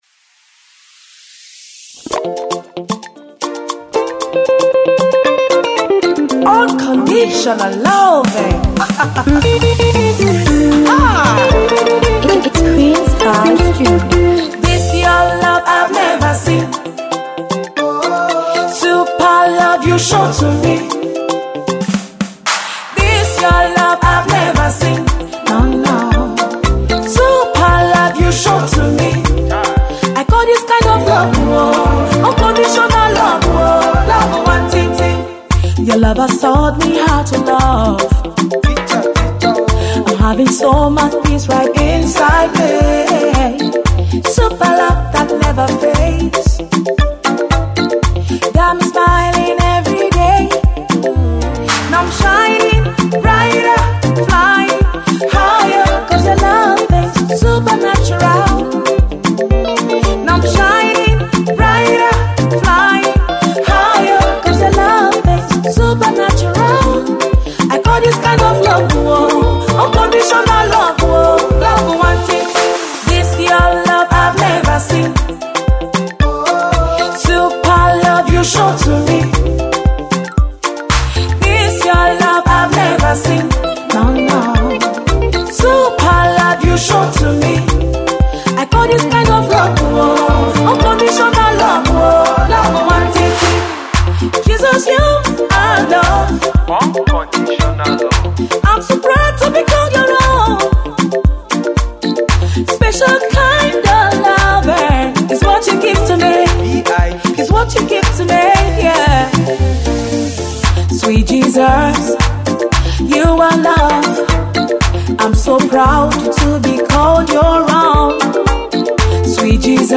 inspirational single